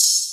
MZ Open Hat [Carti].wav